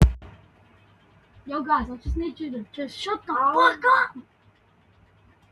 voice record soundboard